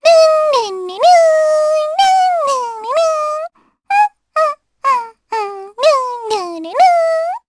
Luna-Vox_Hum_jp.wav